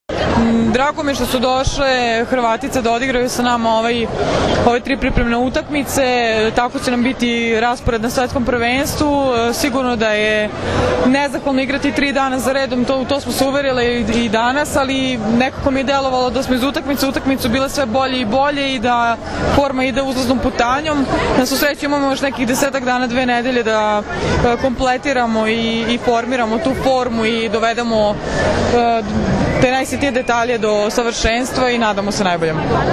IZJAVA JOVANE BRAKOČEVIĆ